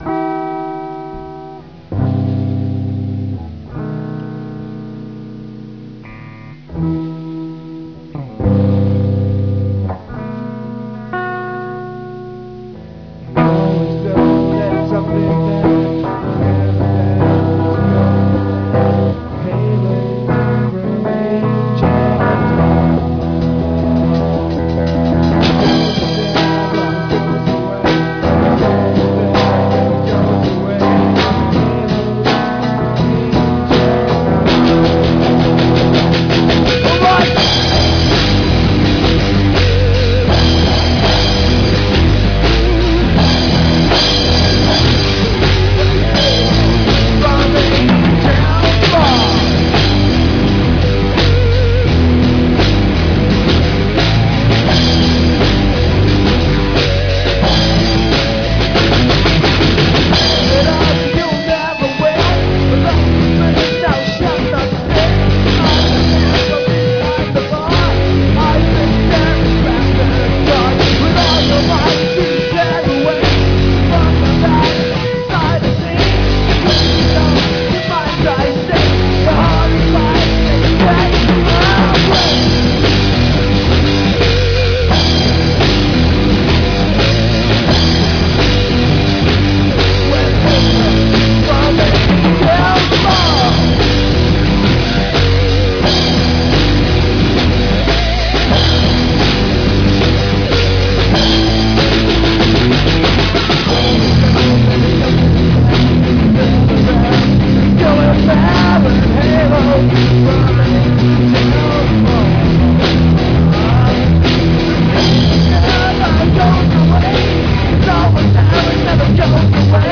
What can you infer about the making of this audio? and using a MD player, plugged in the PA.